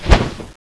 flyzombie_fly_idle.wav